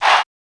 Trap Chant.wav